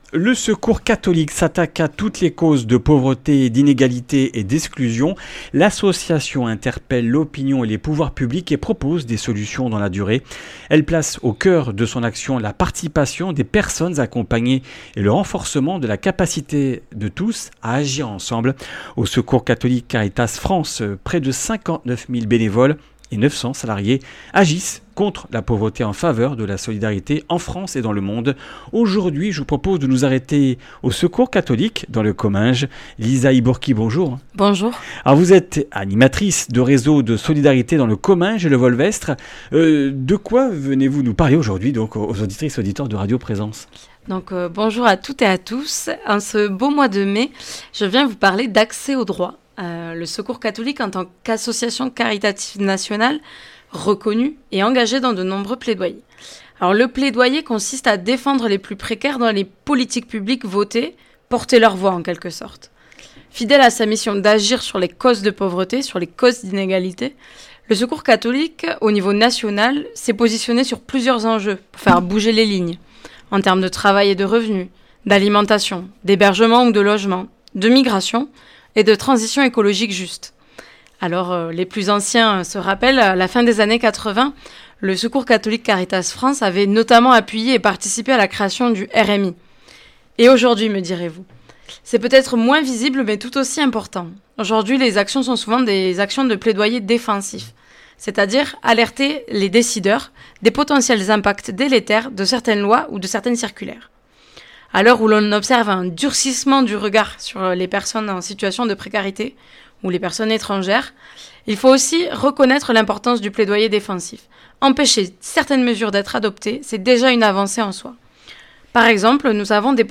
Comminges Interviews du 20 mai
Une émission présentée par